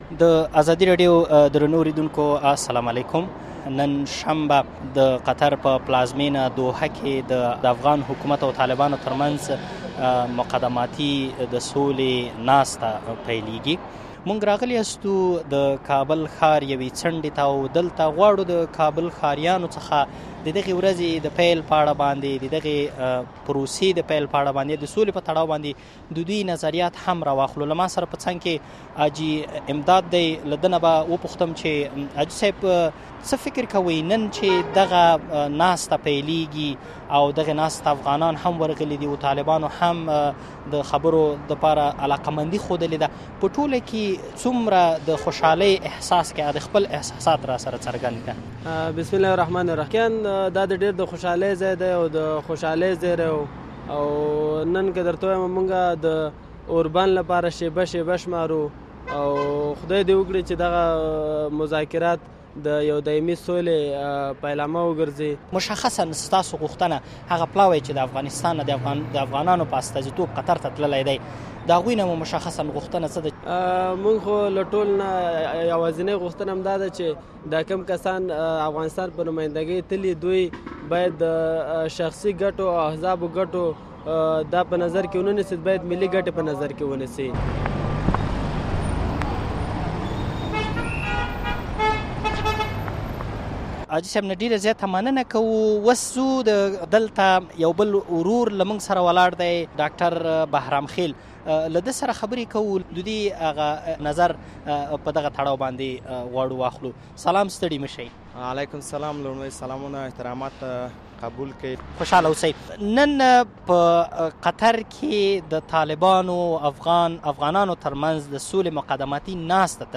راپورتاژ